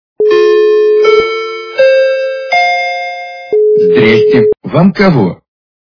» Звуки » Смешные » Звонок в дверь - Здрасте, Вам кого?
При прослушивании Звонок в дверь - Здрасте, Вам кого? качество понижено и присутствуют гудки.
Звук Звонок в дверь - Здрасте, Вам кого?